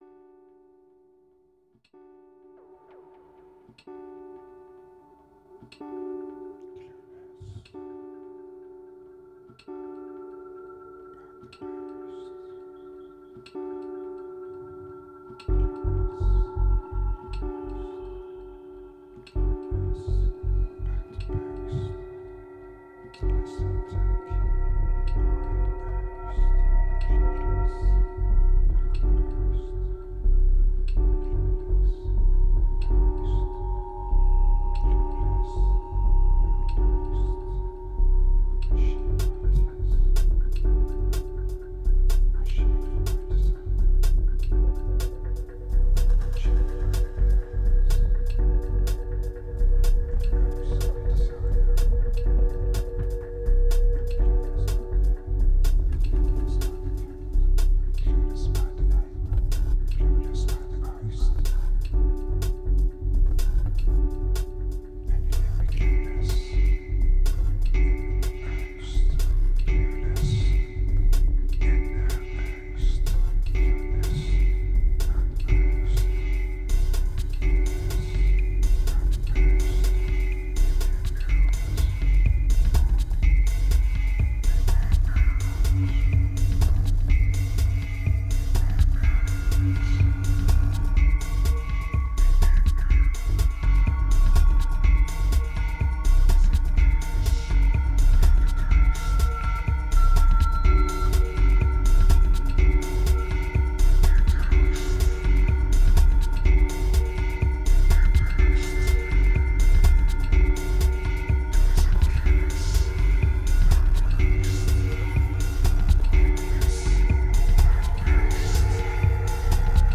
Drowning under massive FX, there still is a part of truth.